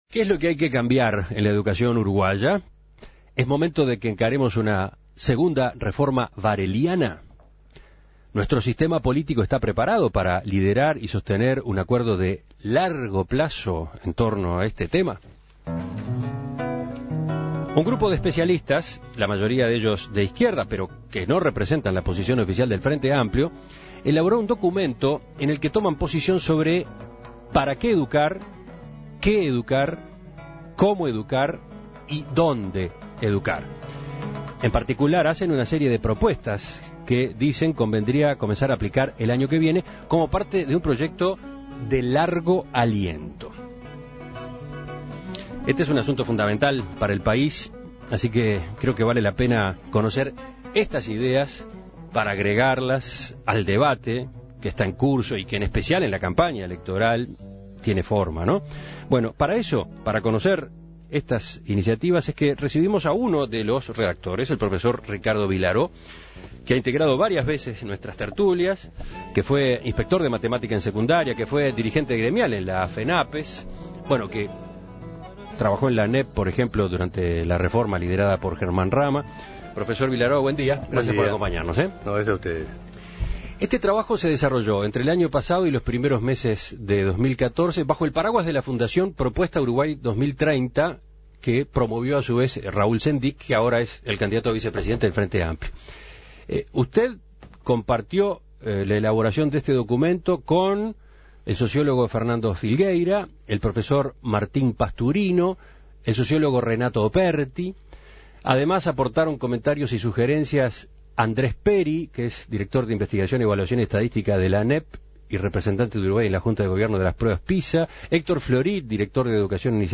Un nuevo documento elaborado por un grupo de especialistas (identificados con la izquierda pero no representan la posición oficial del Frente Amplio) aborda el tema y presentan propuestas que apuntan a la mejora de la calidad de la educación. En Perspectiva entrevistó al profesor